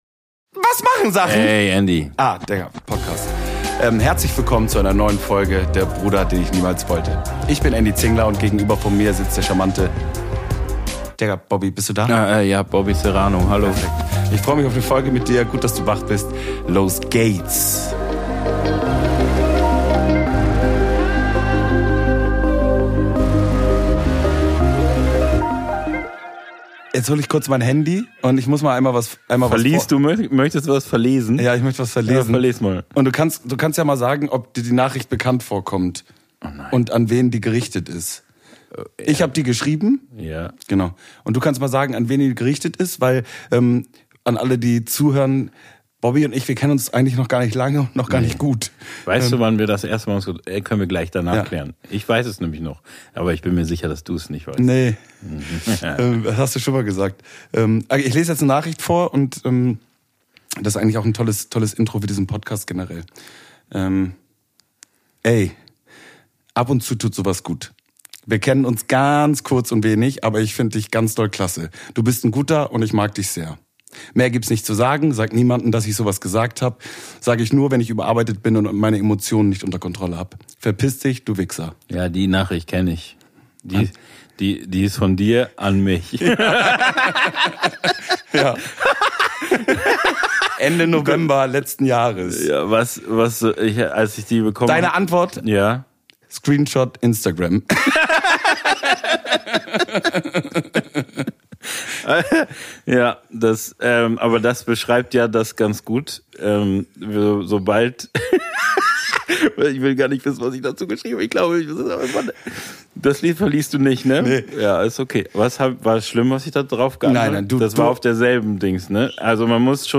Beschreibung vor 10 Monaten Zwei Typen, die sich lieben – und irgendwie reden.
Es geht um Musik, Gefühle, mentale Baustellen, Männlichkeitskrisen und den ganzen anderen Wahnsinn, den man sonst nur nachts um drei mit besten Freunden bespricht. Kein Konzept, kein Skript – aber jede Menge Meinung.